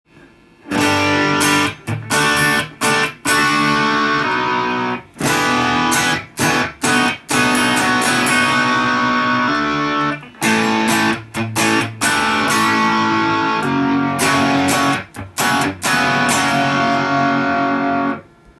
更に一音下げで違和感がないのか実験してみました